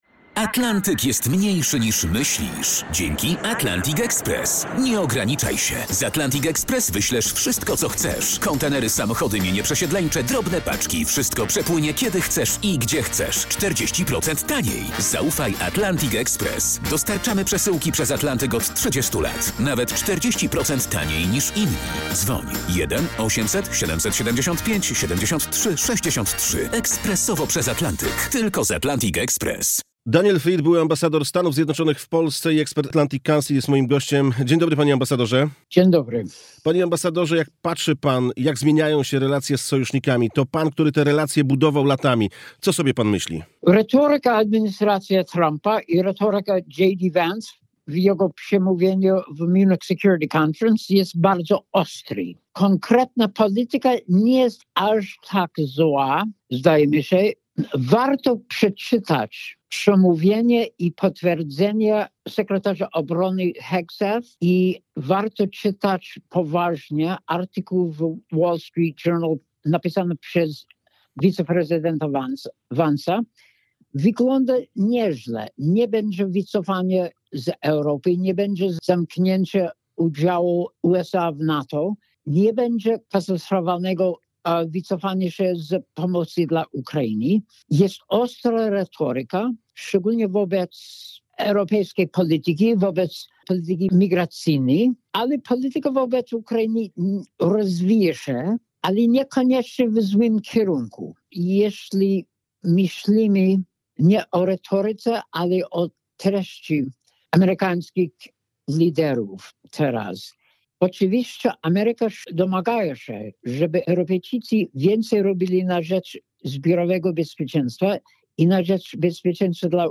Posłuchajcie intrygujących wywiadów z ciekawymi ludźmi. Za mikrofonem dziennikarze RMF FM.